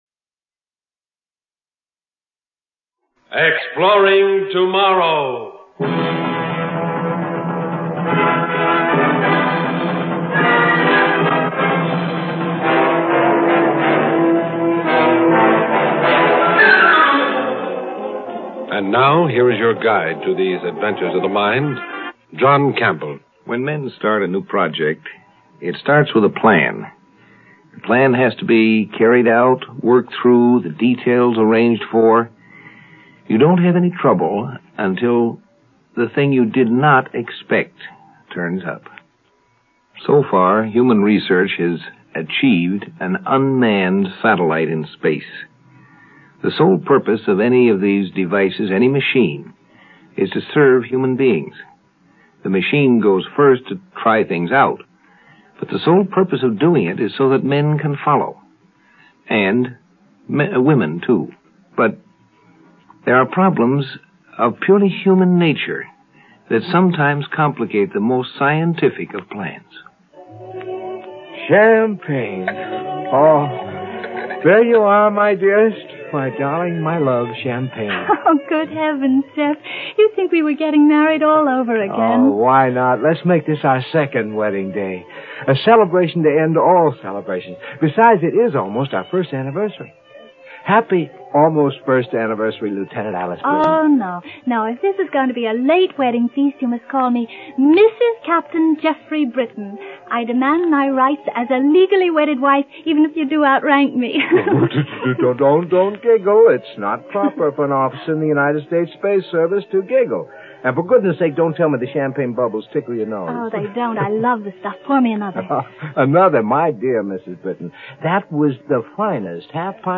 Exploring Tomorrow Science Fiction Radio Program